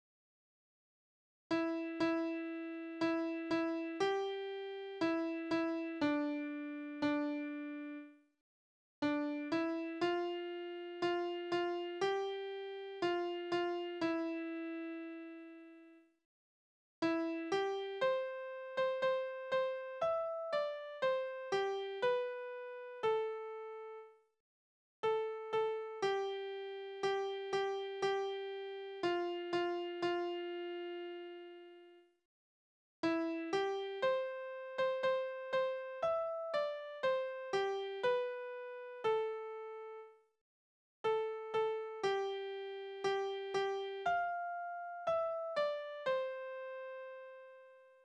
Naturlieder
Tonart: C-Dur
Taktart: C (4/4)
Tonumfang: kleine Dezime
Besetzung: vokal